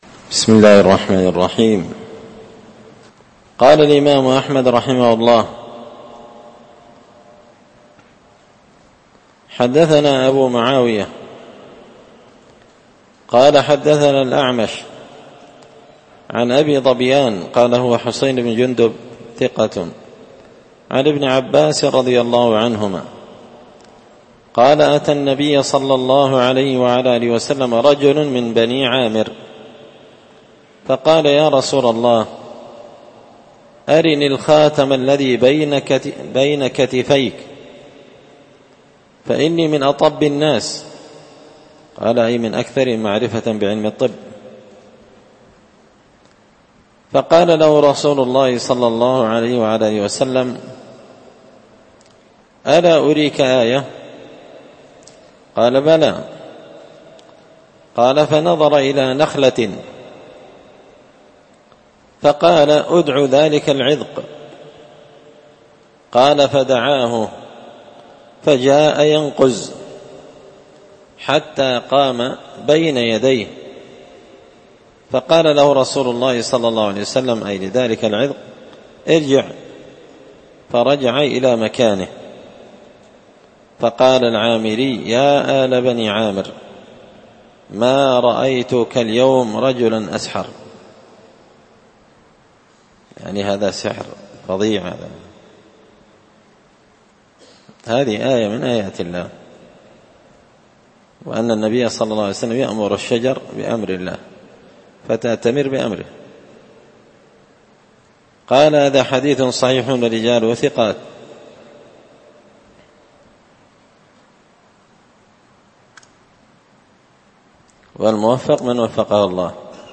الدرس 121 فصل من عجائب قدرة الله الخارقة للعادة
دار الحديث بمسجد الفرقان ـ قشن ـ المهرة ـ اليمن